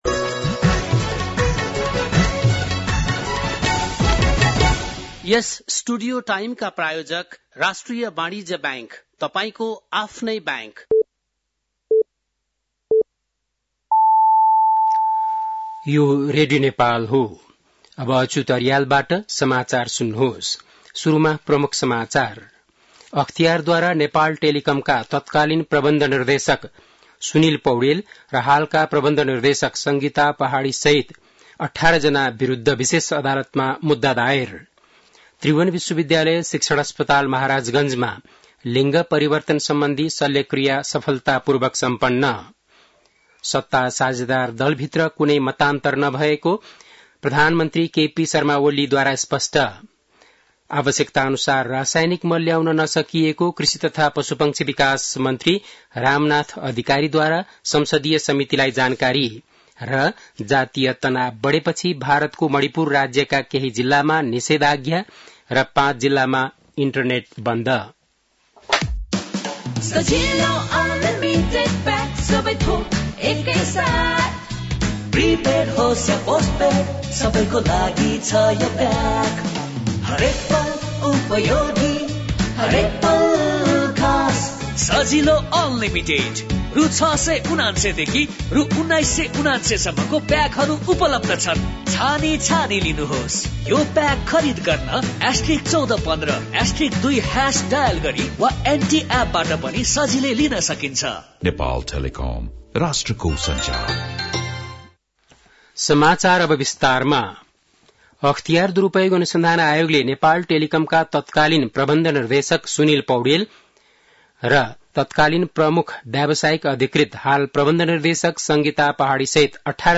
बेलुकी ७ बजेको नेपाली समाचार : २५ जेठ , २०८२
7-pm-nepali-news-2-25-.mp3